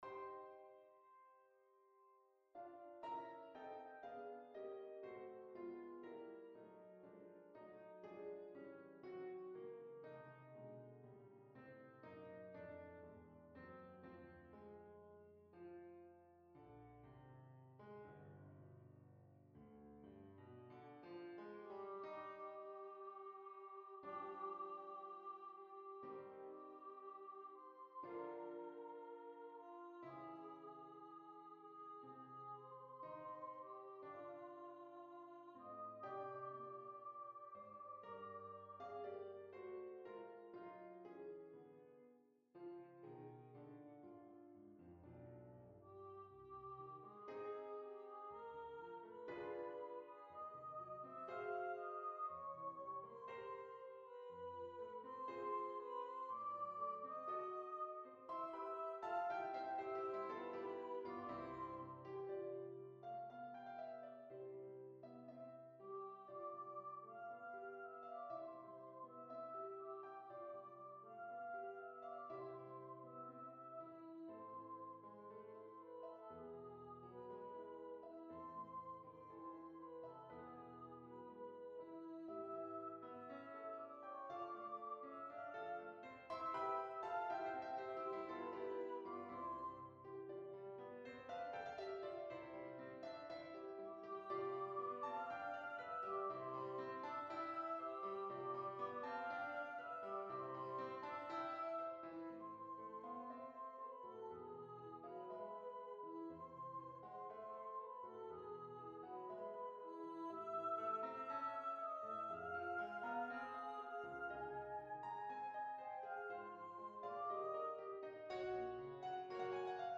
Voices: Solo Voice Instrumentation: Piano
NotePerformer 4 mp3 Download/Play Audio